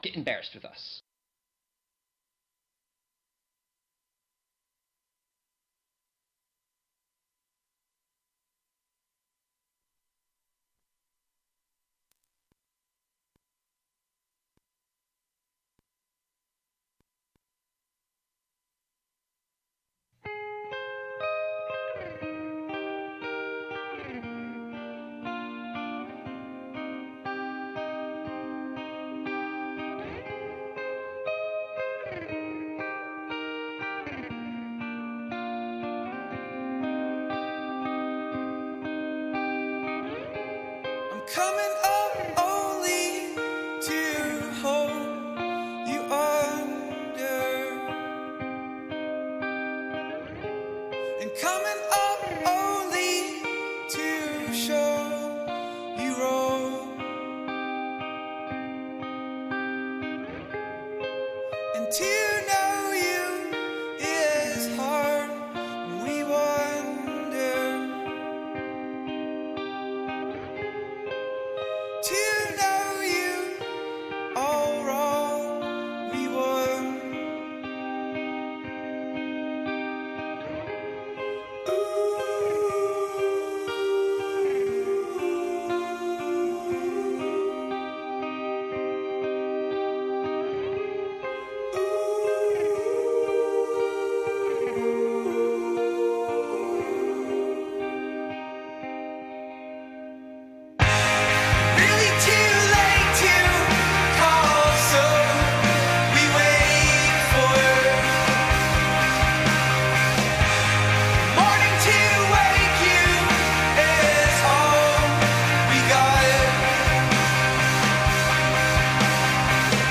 we have guests, and sometimes just ourself and a caller from time to time.